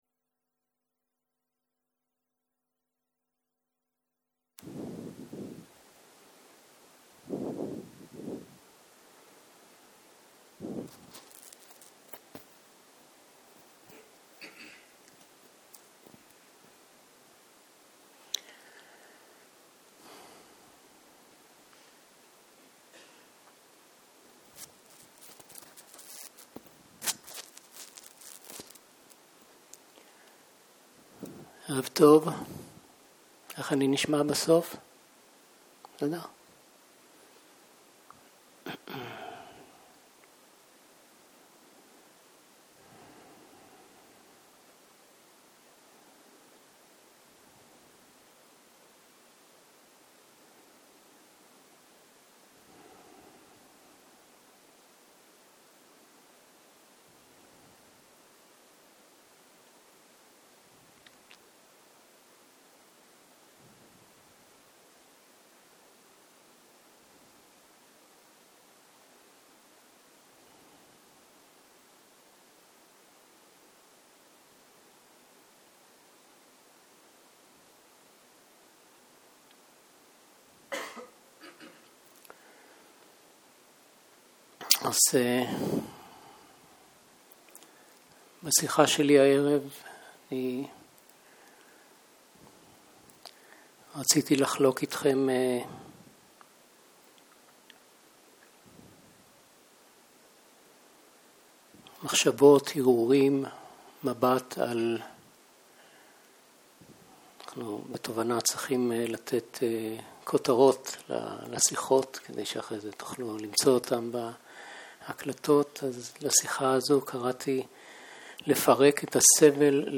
Dharma type: Dharma Talks